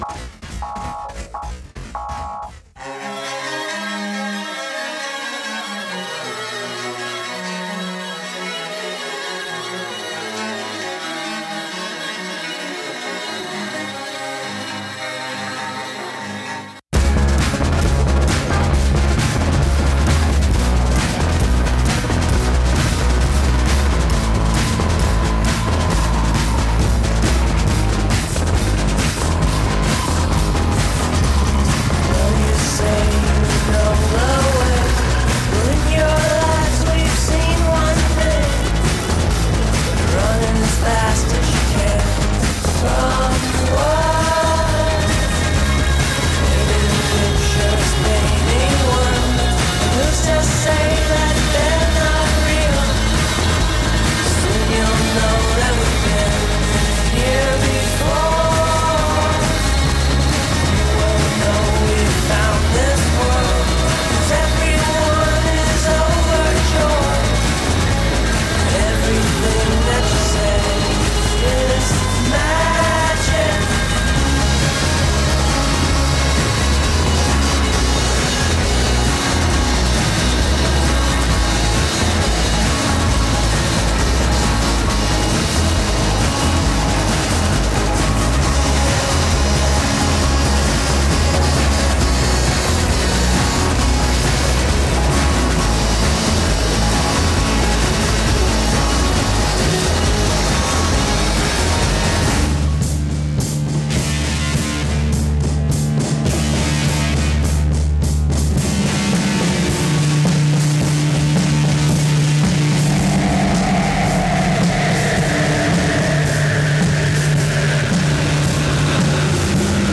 התופים